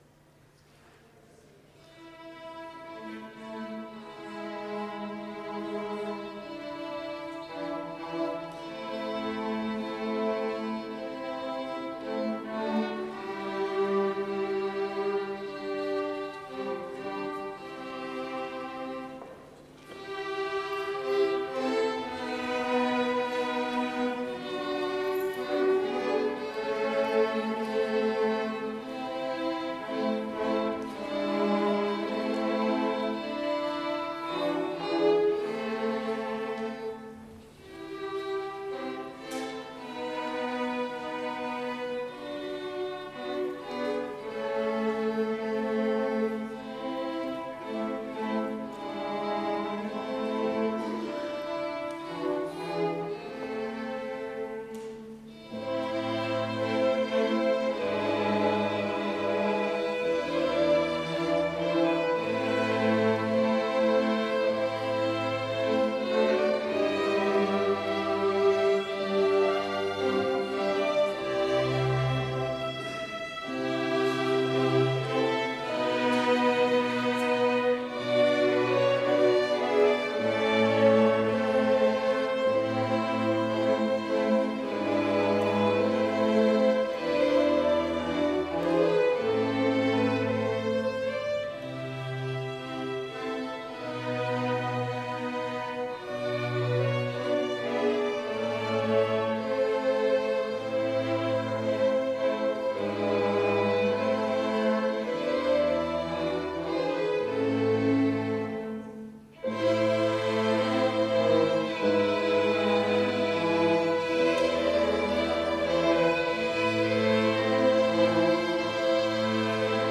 Complete service audio for Chapel - February 7, 2020